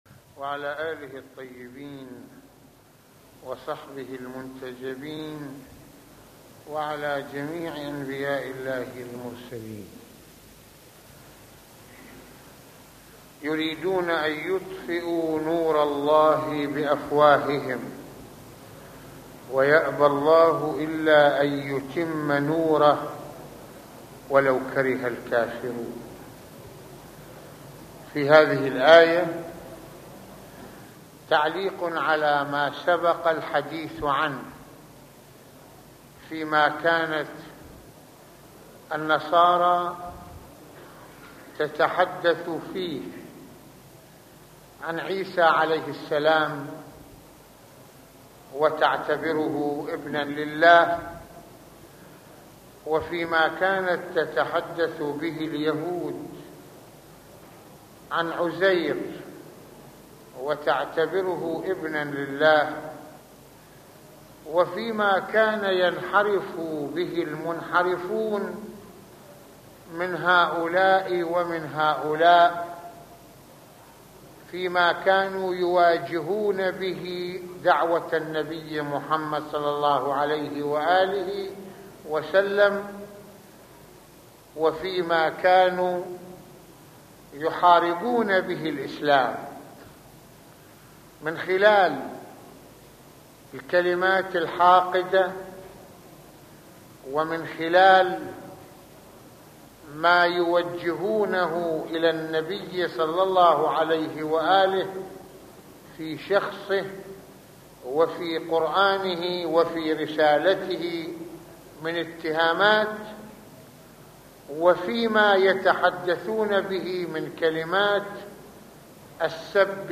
- يتحدث سماحة المرجع السيد محمد حسين فضل الله "رض" في هذه المحاضرة القرآنية عن ضرورة التمسك بنور الحق والحقيقة المتمثل بسبيل الله الذي لا تقدر أن تمحوه شبهات الجاهلين ومؤامرات المبطلين ، من هنا علينا في كل زمان الانتصارللحقيقة والحق على الباطل مهما غلت التضحيات وحاول البعض المكابرة والمعاندة، فسلاح المؤمن الوعي والعمل بإخلاص للإسلام ورفعته كي نكون شهداء على الناس من خلال الثقة بالله والاقتداء برسوله الكريم ...